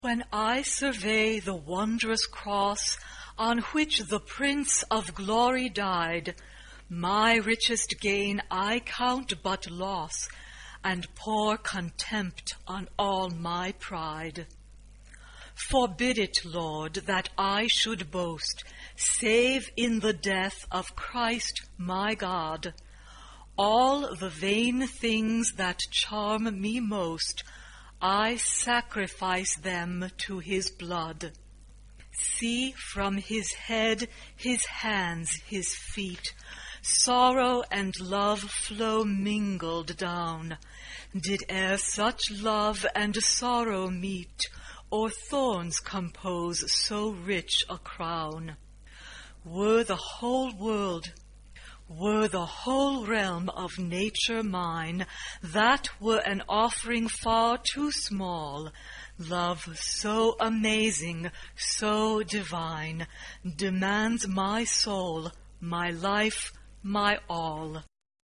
SATB (4 voices mixed).